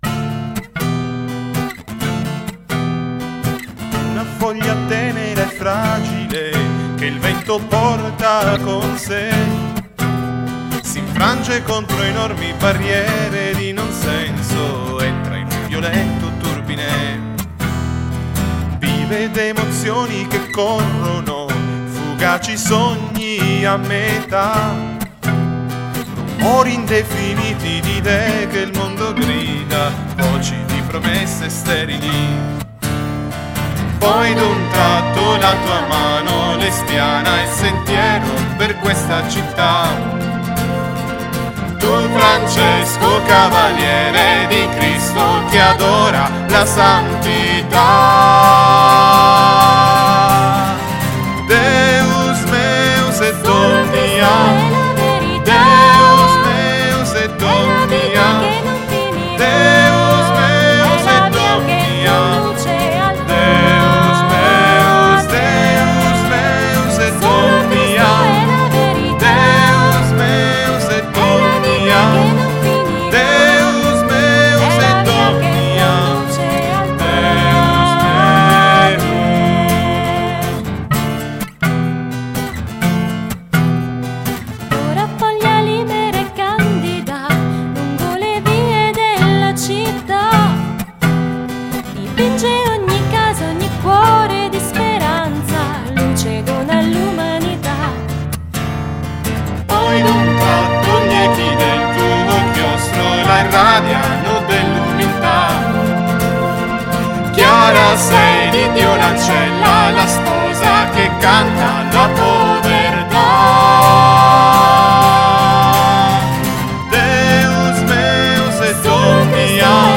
Hymna stretnutia.